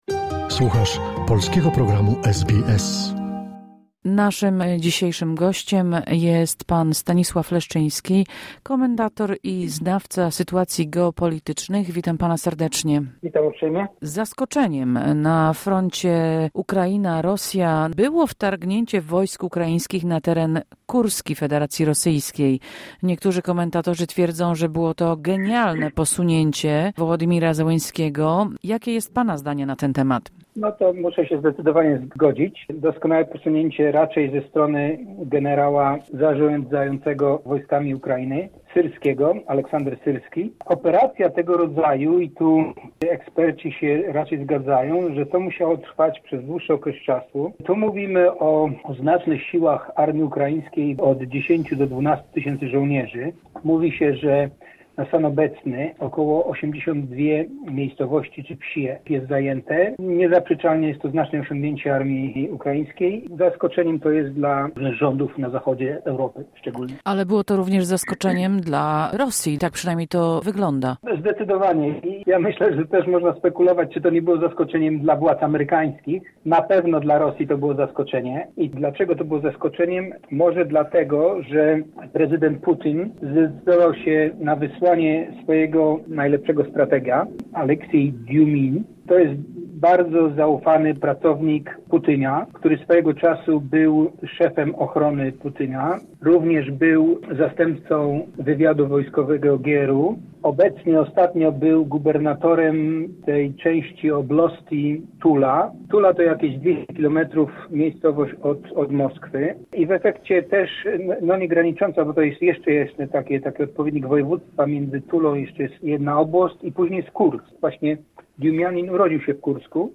Do jakiego stopnia ukraiński atak na dłuższą metę bedzie skuteczny i jak na to odpowie Rosja - w rozmowie…